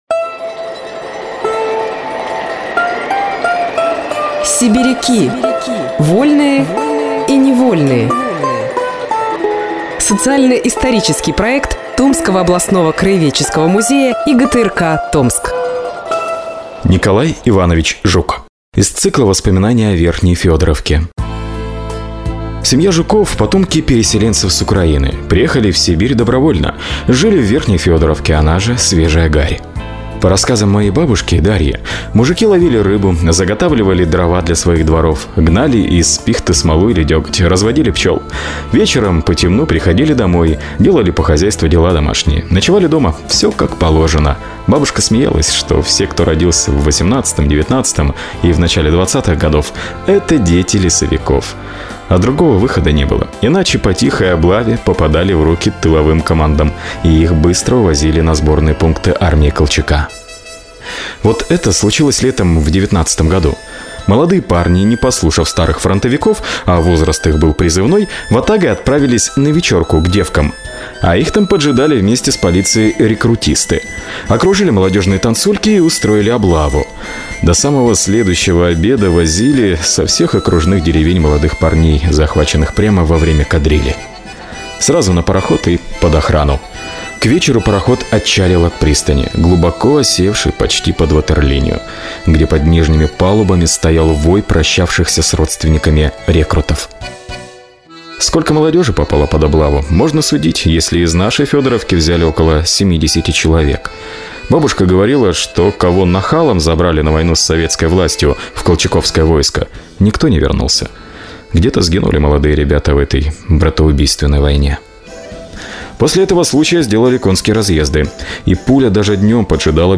Мужской голос
Женский